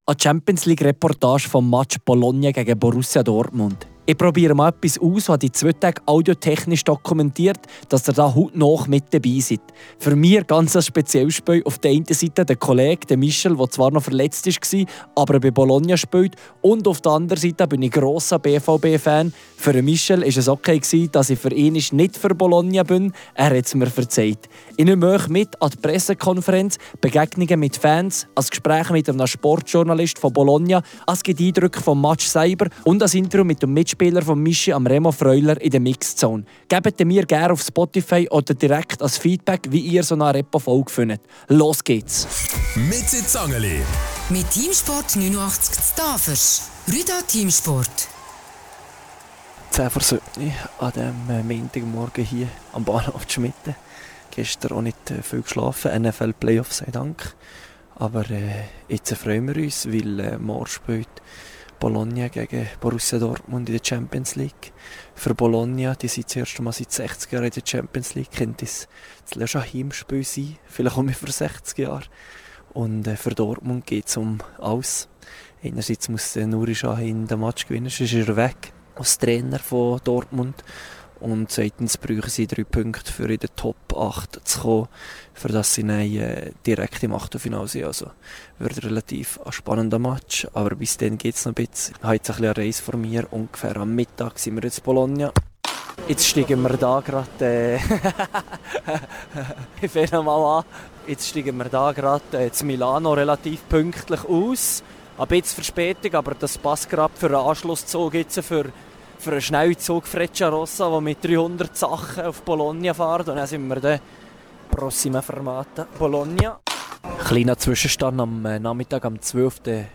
Ich nehme euch mit an die Pressekonferenz und an Begegnungen mit Fans. Es gibt ein Gespräch mit einem Sportjournalisten aus Bologna und Eindrücke des Spiels sowie ein Interview mit Aebischers Teamkollegen im Club und in der Nati, Remo Freuler.